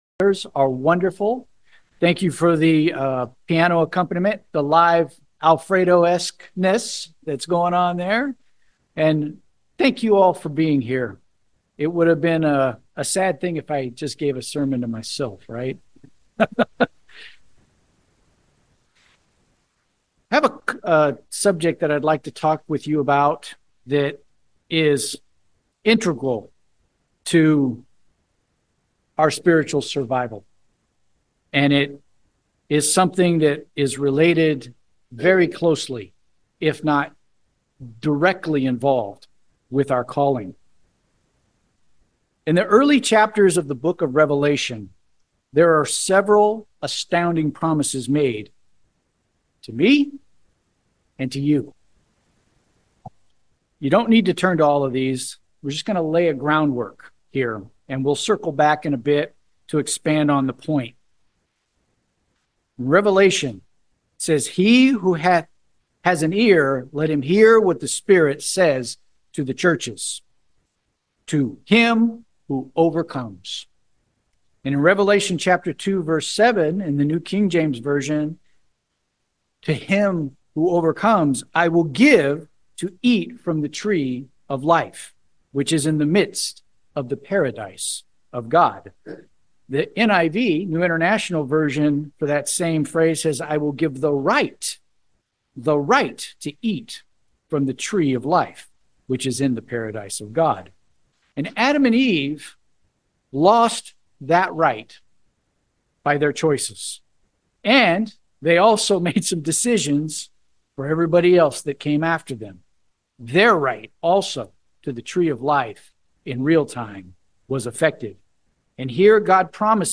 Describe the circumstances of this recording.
Given in San Francisco Bay Area, CA San Jose, CA Petaluma, CA